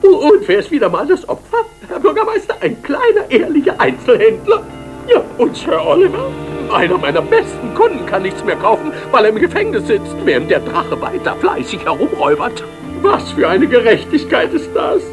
Ich hätte da mal eine Münchner Trick-Synchro von 1989 mit den üblichen Verdächtigen.
Händler